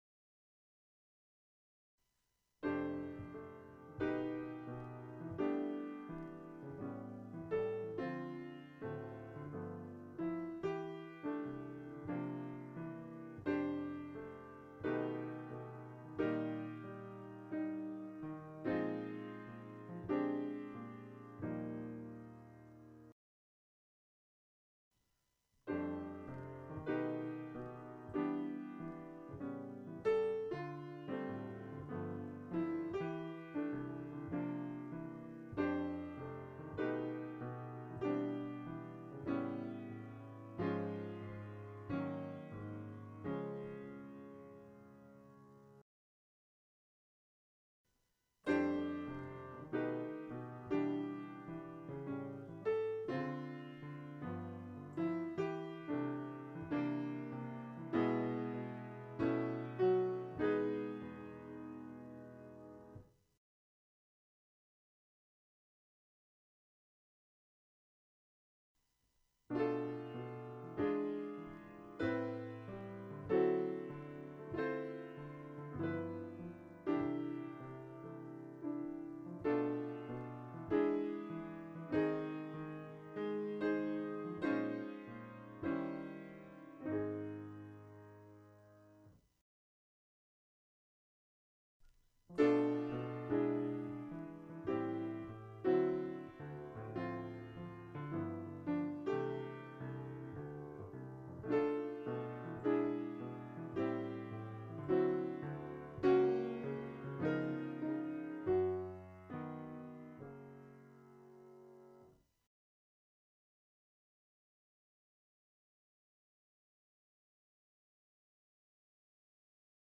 (Das Eintreten von I/5th klingt immer sehr erfrischend.) :)
Hörbeispiel mit allen 5 Sek. Doms.